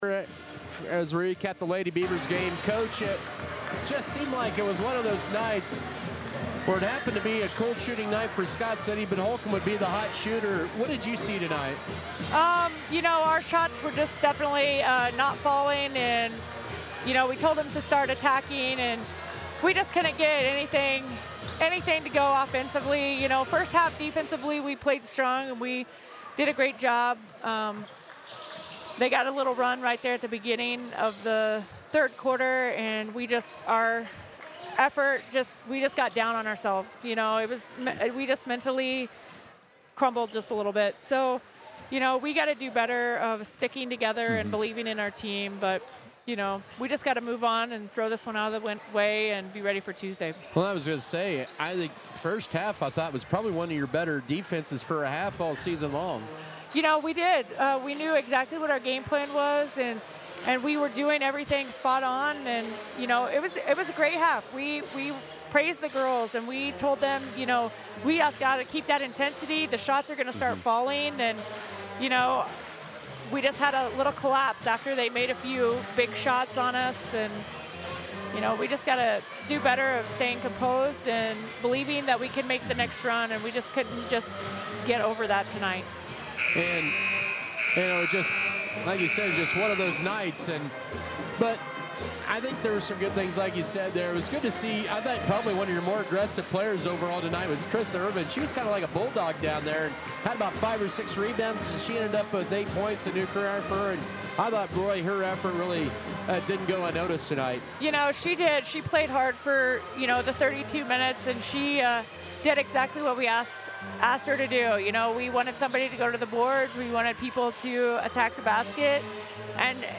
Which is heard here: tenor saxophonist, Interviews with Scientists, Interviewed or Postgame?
Postgame